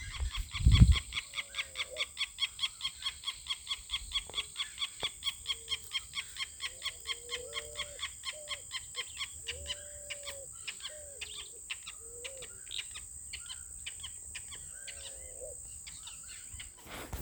Wattled Jacana (Jacana jacana)
Province / Department: Entre Ríos
Condition: Wild
Certainty: Observed, Recorded vocal